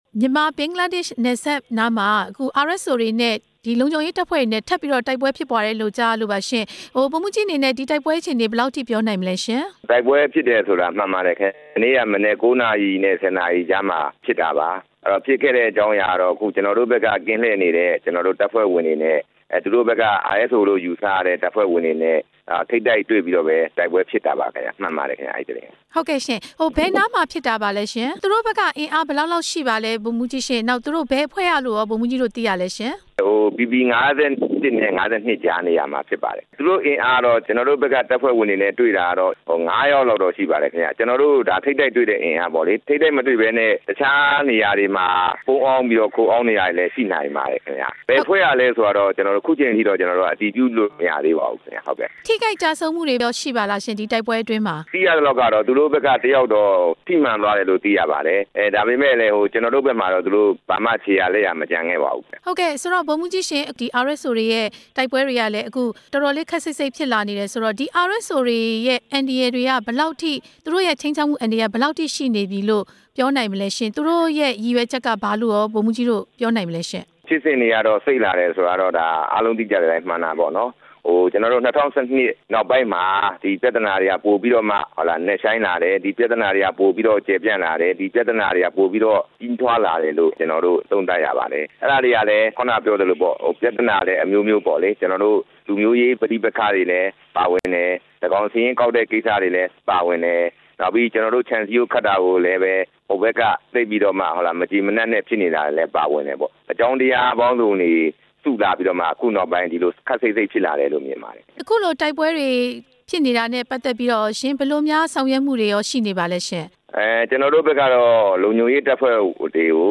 မြန်မာစစ်တပ်အရာရှိတစ်ဦး နဲ့ ဆက်သွယ်မေးမြန်းချက်